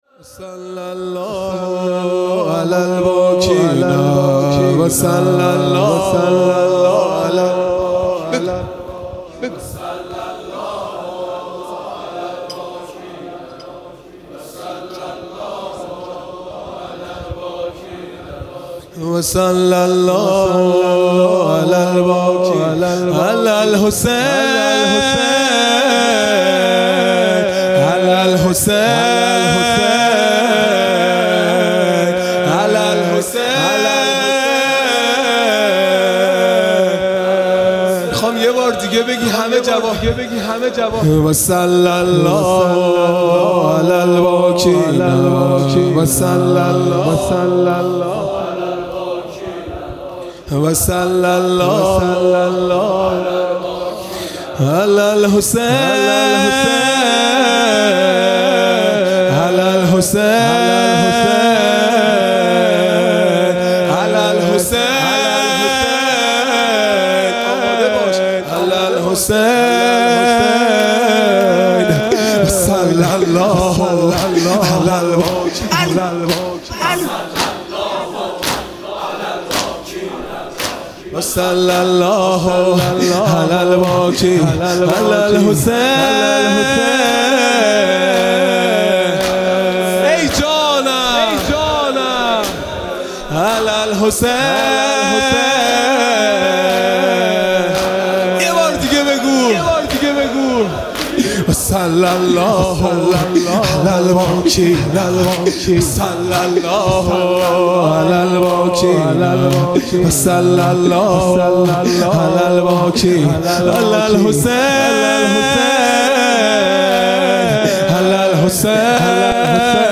عزاداری دهه آخر صفر المظفر (شب اول)